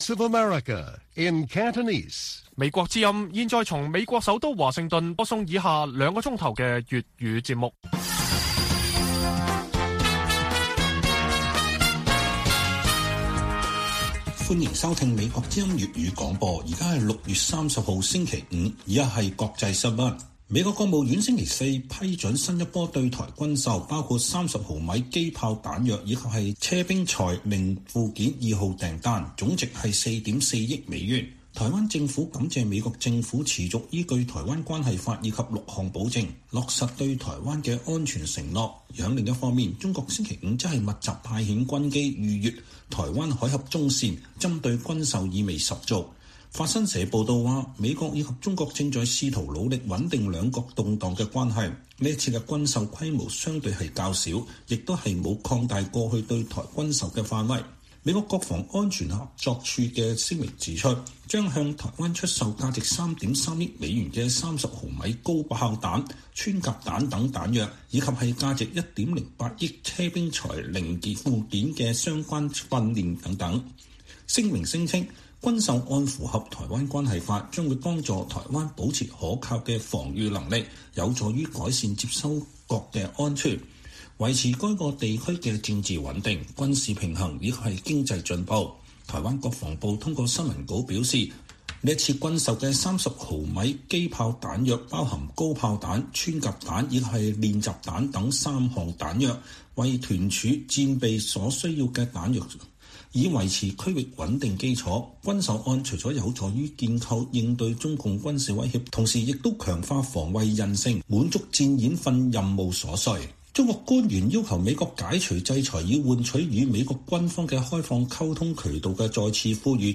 粵語新聞 晚上9-10點: 布林肯說美國尋求與中國和平共處